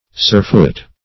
Surfoot \Sur"foot`\, a. Tired or sore of foot from travel; lamed.